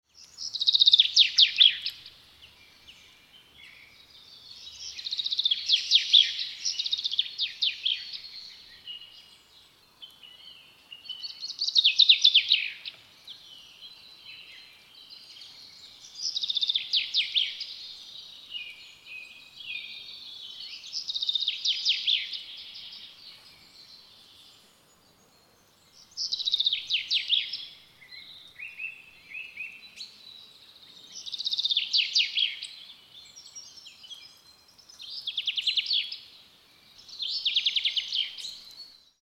Listen to how the birds worship their Heavenly Father!!!
bird_singing.mp3